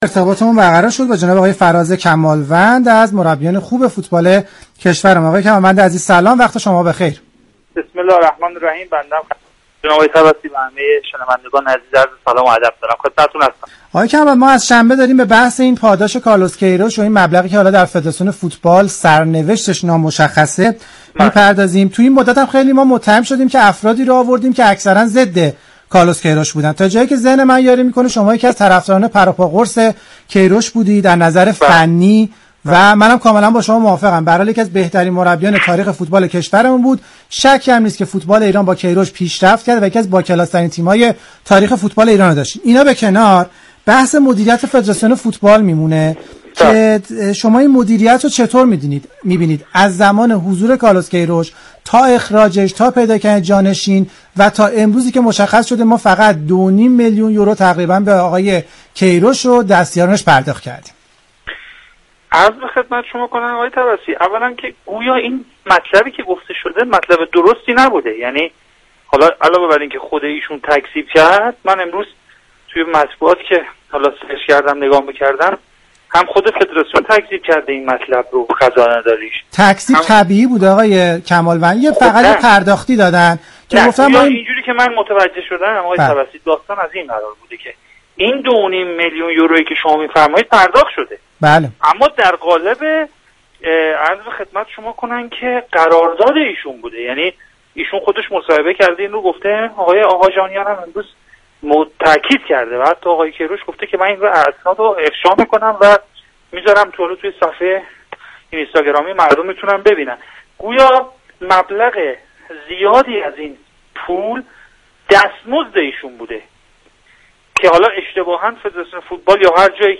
به گزارش رادیو ورزش؛ فراز كمالوند، مربی فوتبال، در خصوص پاداش كارلوس كی روش و نحوه مدیریت فدراسیون فوتبال با رادیو ورزش گفتگویی انجام داد.